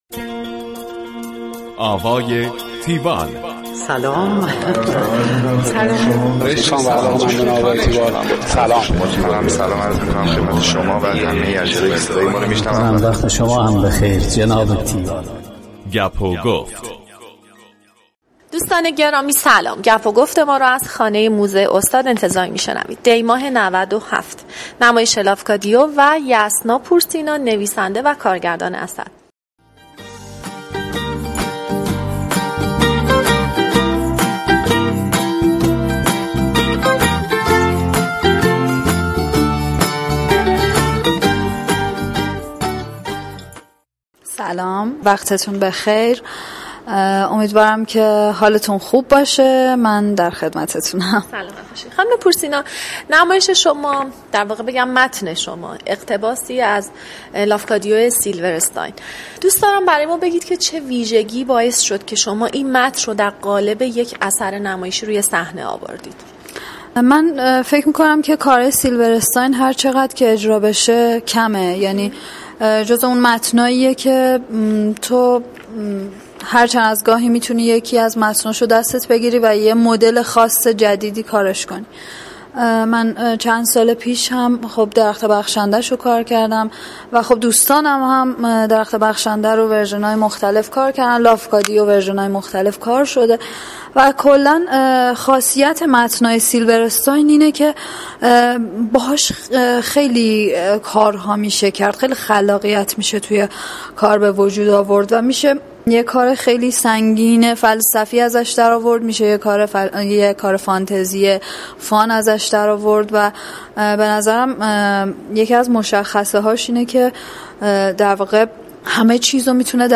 گفتگوی تیوال